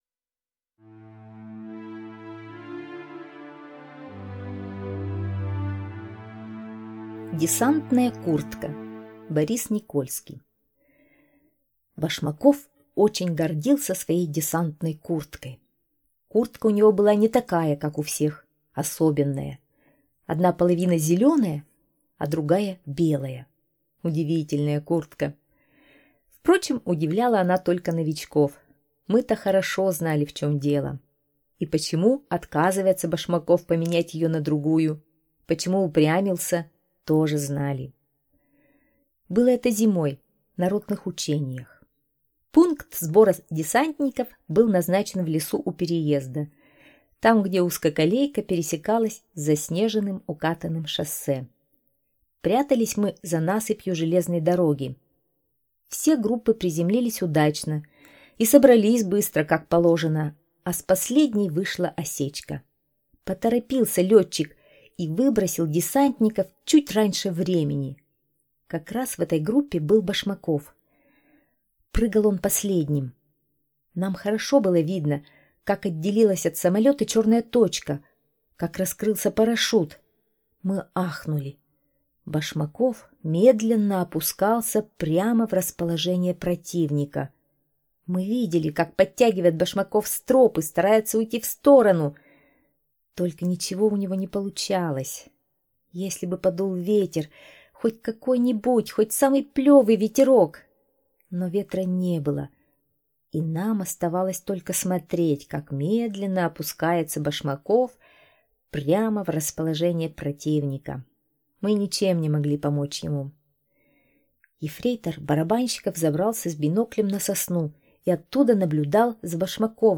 Аудиорассказ «Десантная куртка»